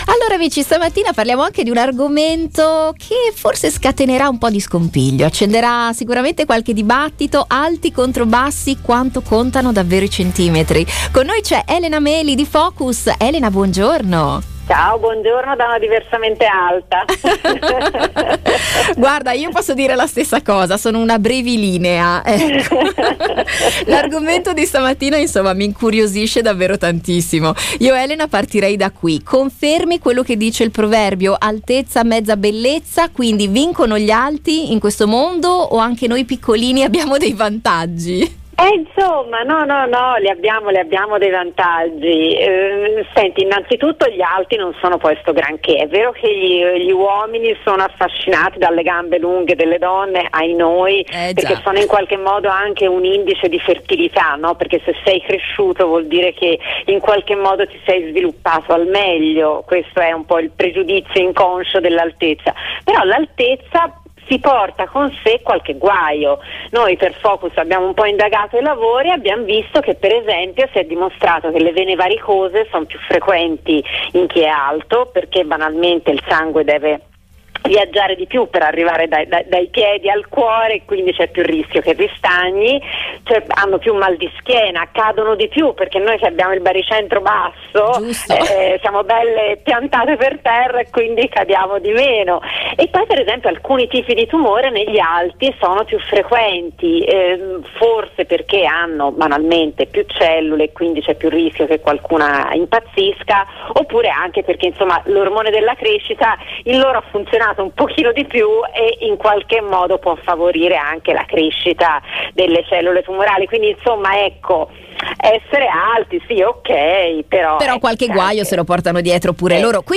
Da cosa è influenzata l’altezza, a quale età si raggiunge quella definitiva, chi sono i più alti al mondo, sono alcune delle curiosità investigate nell’intervista, alla fine della quale scopriremo anche di chi sarà il futuro.